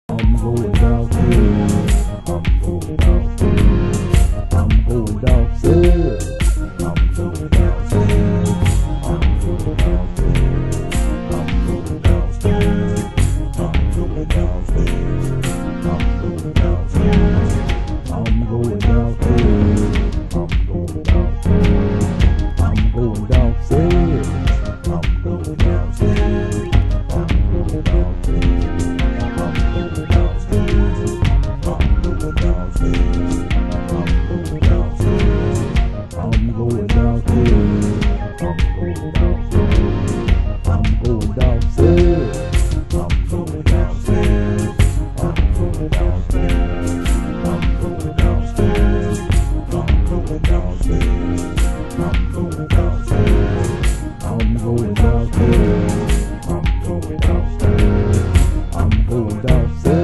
○中盤〜終盤あたりで交錯する音の共鳴は素晴らしいDOPE/JAZZ！